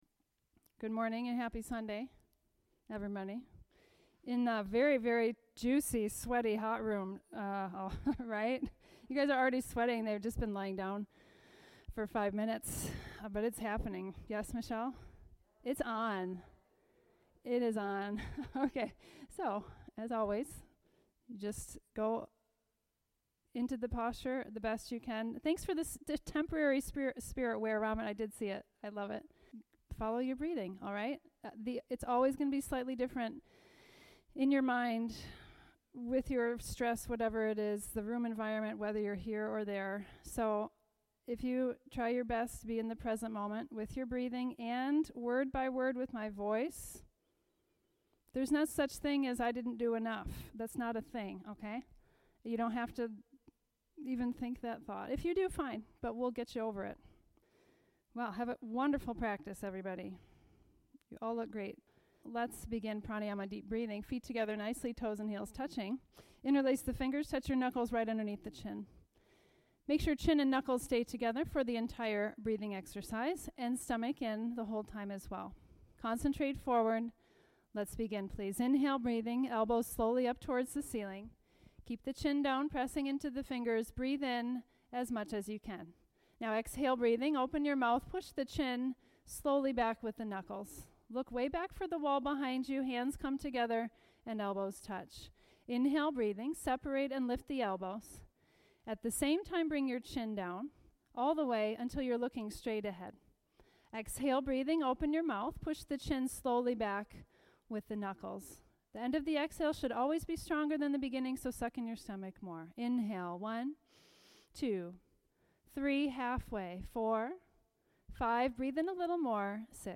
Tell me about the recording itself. Where Do You Go When You Leave the Present Moment? | Advice for Practicing with a Baker's Cyst Where "Knee-Locking" Hurts | Sunday, November 25, 2020 | 10:00 am class | Bikram Yoga | The Original Hot Yoga!